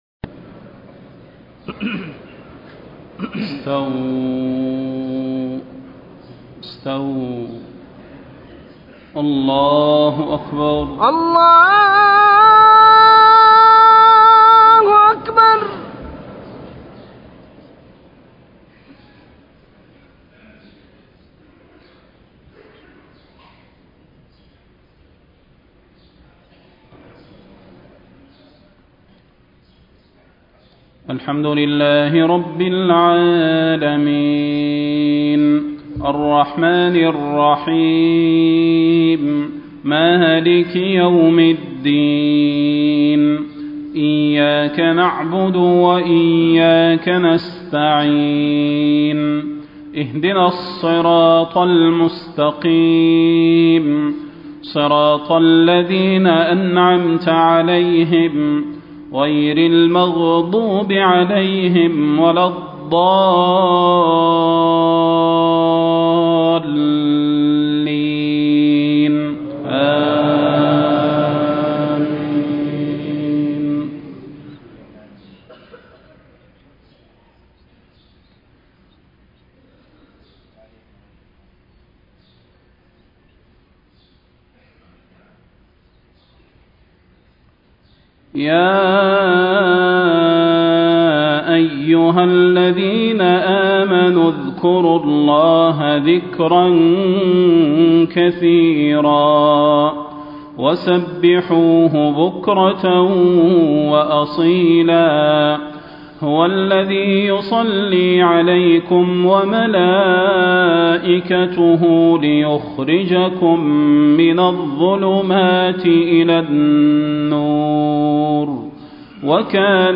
صلاة المغرب 1 - 4 - 1434هـ من سورة الأحزاب > 1434 🕌 > الفروض - تلاوات الحرمين